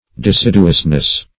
Search Result for " deciduousness" : The Collaborative International Dictionary of English v.0.48: Deciduousness \De*cid"u*ous*ness\, n. The quality or state of being deciduous.
deciduousness.mp3